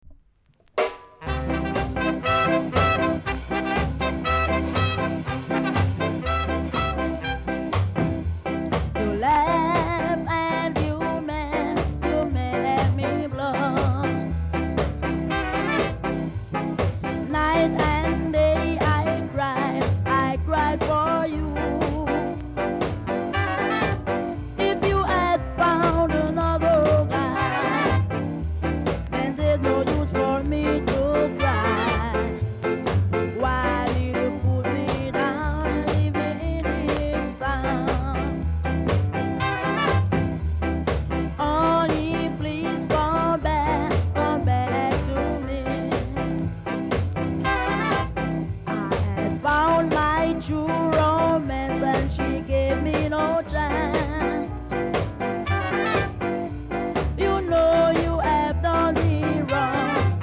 ※小さなチリ、パチノイズが少しあります。盤は細かい薄い擦り傷がやや目立ちますが、音はマシな方だと思います。
コメント NICE SKA VOCAL!!※レーベル裏面に書き込みあります。